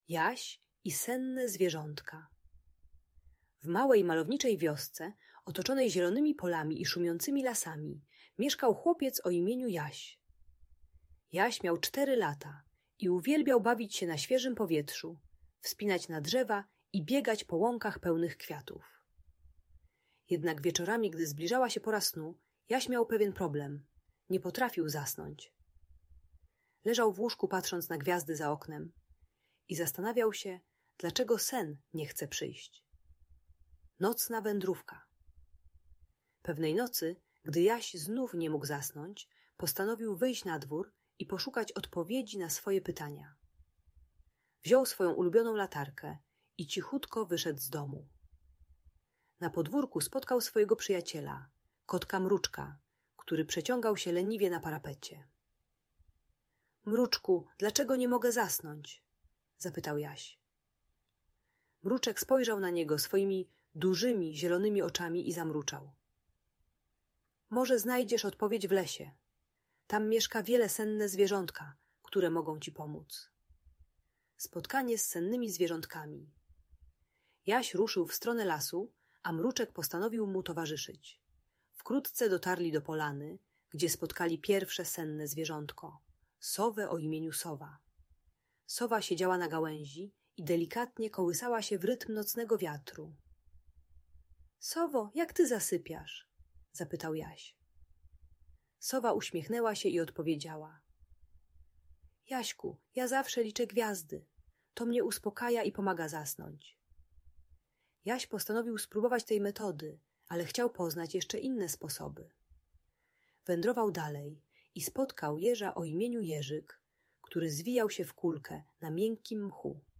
Ta bajka dla dziecka które nie może zasnąć uczy techniki wizualizacji - wyobrażania sobie spokojnego jeziora. Audiobajka usypiająca dla przedszkolaków 3-5 lat z rytuałem relaksacyjnym na dobranoc.